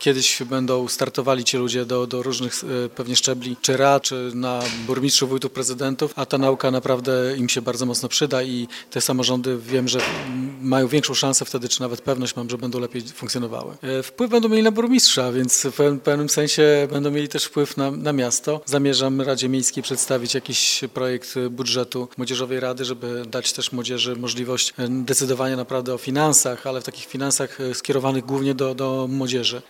Burmistrz Giżycka Wojciech Iwaszkiewicz dostrzega potrzebę istnienia Młodzieżowej Rady Miasta. Ważne jest, by młodzież poznawała mechanizmy działające w samorządzie – mówi szef giżyckiego Urzędu Miejskiego.